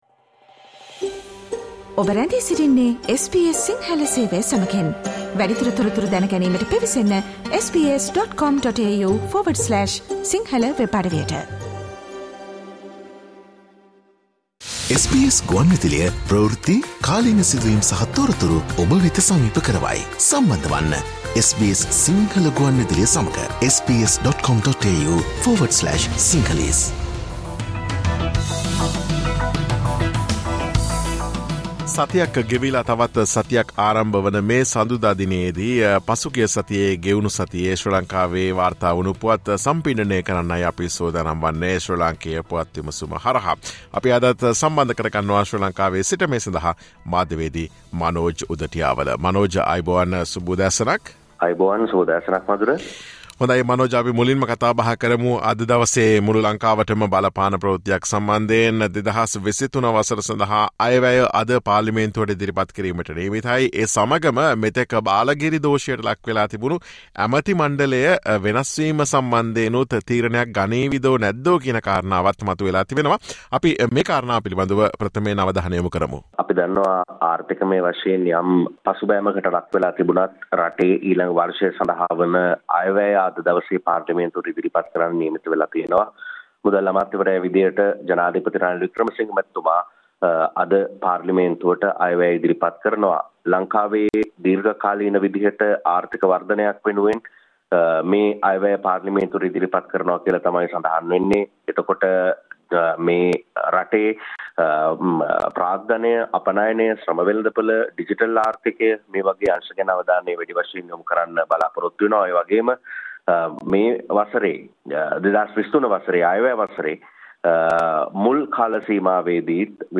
Sri Lanka’s Weekly Political Highlights
SBS Sinhala radio brings you the most prominent news highlights of Sri Lanka in this featured current affair segment every Monday.